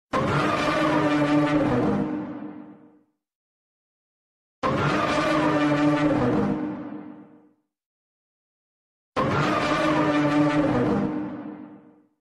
Оглушающе